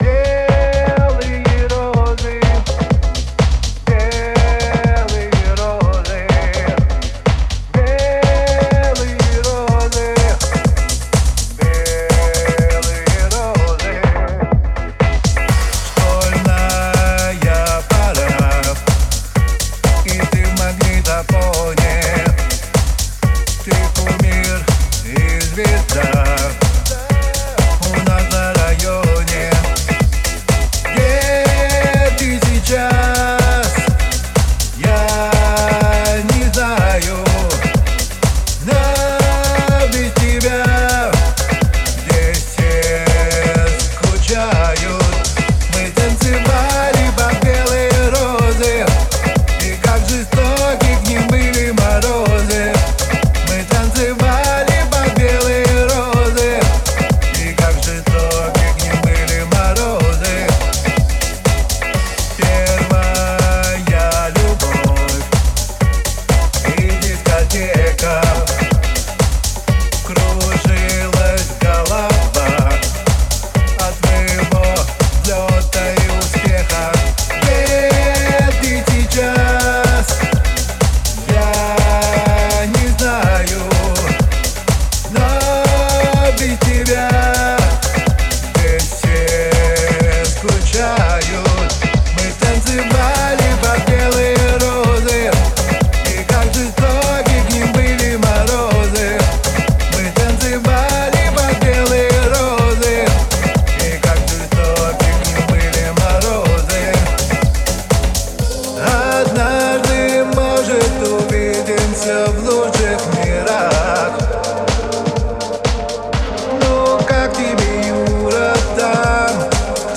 Прикрепляю трек с механическим масренигом, кто шарит и возжелает помочь, напишите на почту...
Не знаю, что вам не нравится, но думаю голос в миксе. Чуток тела ему добавил.